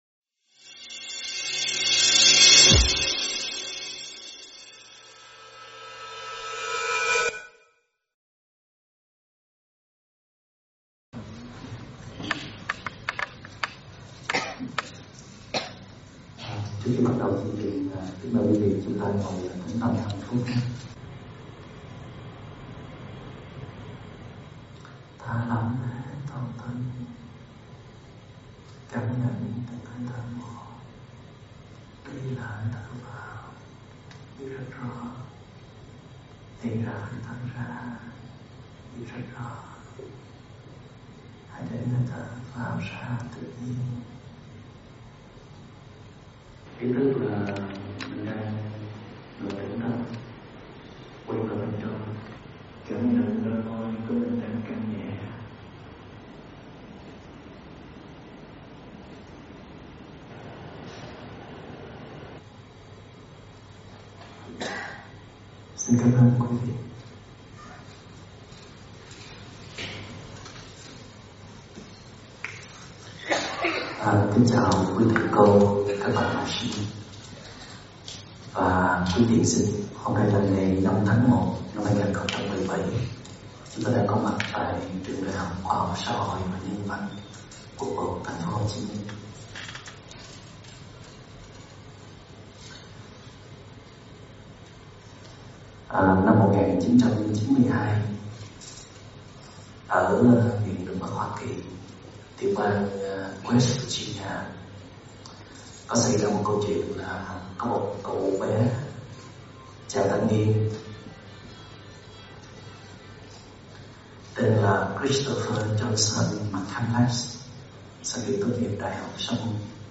Nghe mp3 thuyết pháp Sức Mạnh Của Trái Tim Tỉnh Thức do TS. Thích Minh Niệm giảng tại ĐH Xã Hội và Nhân Văn, TP. Hồ Chí Minh ngày 5 tháng 1 năm 2017
Hồ Chí Minh ngày 5 tháng 1 năm 2017 Mp3 Thuyết Pháp Thích Minh Niệm 2017 Thuyết pháp Thích Minh Niệm